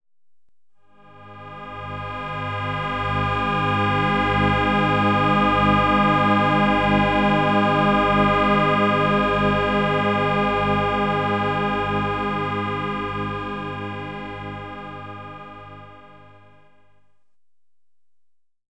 Gis mit 408 Hz und 432 Hz Kammerton,
mit Schwebung im Theta Bereich,
inklusive tieferen Gis Oktaven
Gis_408Hz_Gelassenheit.wav